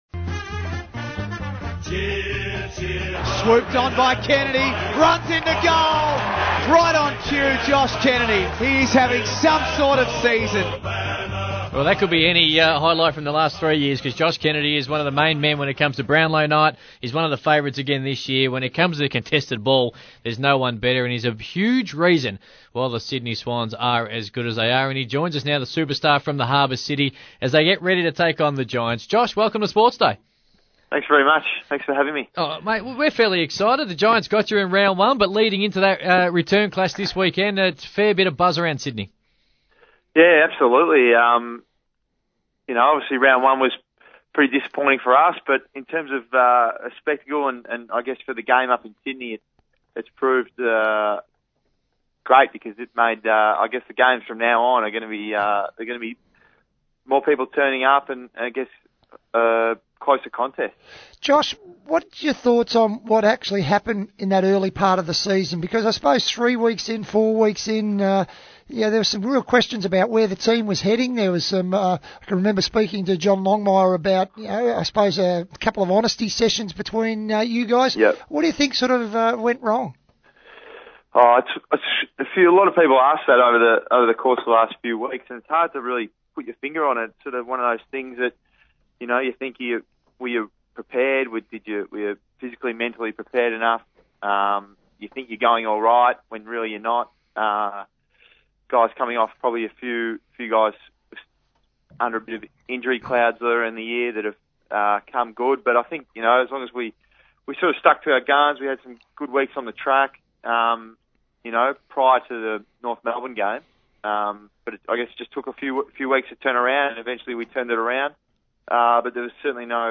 Swans on-baller Josh Kennedy appeared on CrocMedia's SportsDay Radio on Thursday June 26, 2014